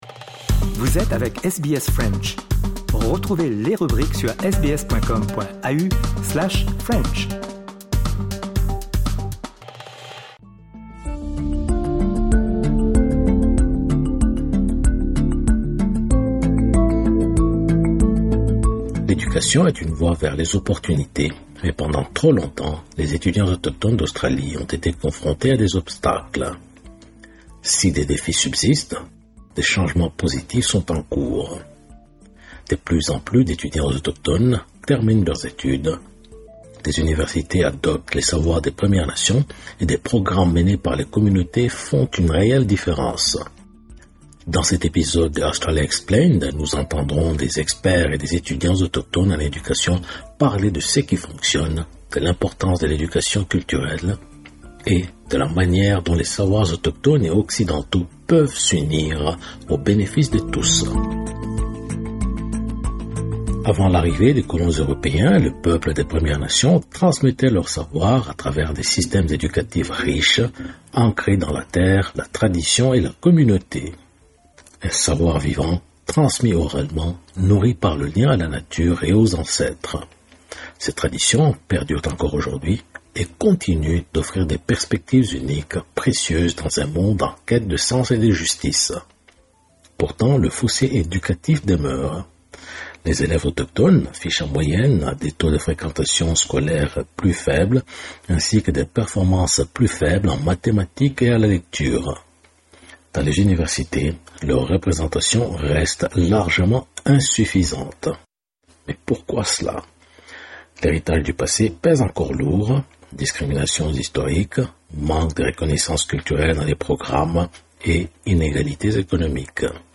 De plus en plus d'étudiants autochtones terminent leurs études, les universités adoptent les savoirs des Premières Nations, et des programmes menés par les communautés font une réelle différence. Dans cet épisode de Australia Explained, nous entendrons des experts et des étudiants autochtones en éducation parler de ce qui fonctionne, de l'importance de l'éducation culturelle, et de la manière dont les savoirs autochtones et occidentaux peuvent s'unir au bénéfice de tous